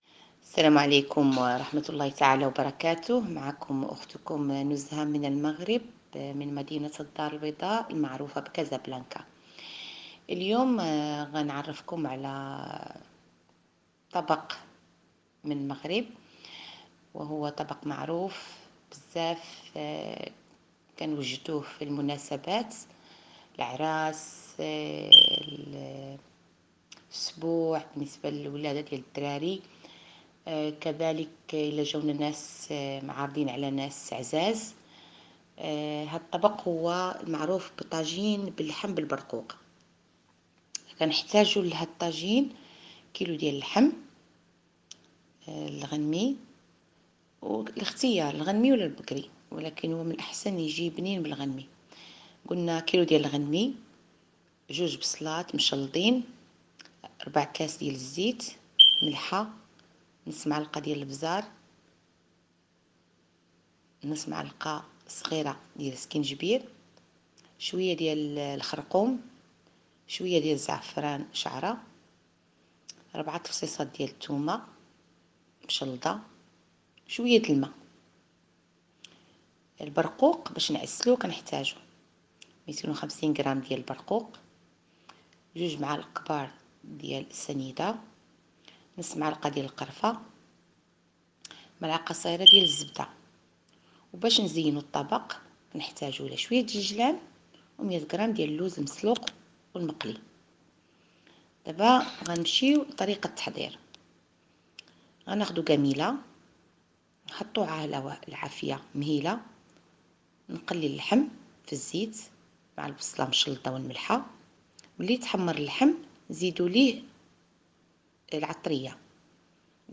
Sample 3 - The Dialects of Arabic (legacy site)